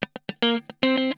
PICKIN 1.wav